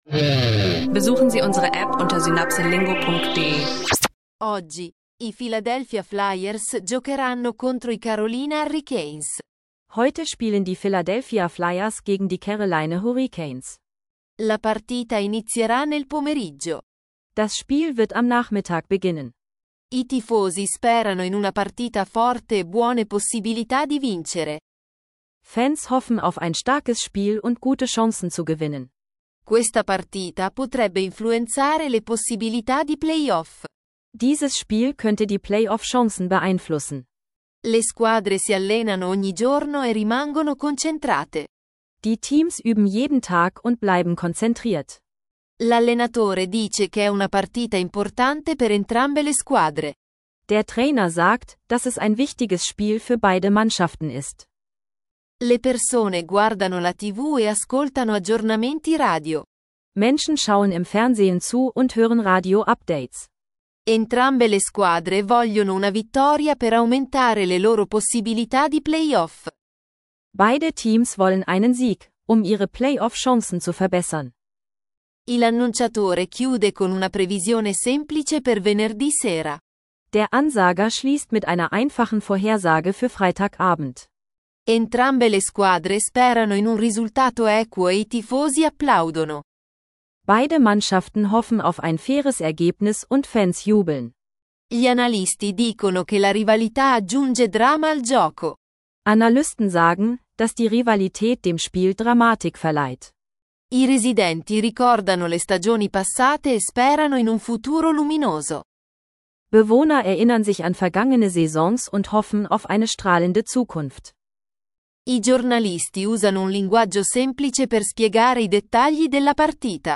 Anfängerfreundlicher News-Style auf Italienisch über NHL-Spielphasen, Rivalität und Playoff-Chancen – leicht verständlich lernen.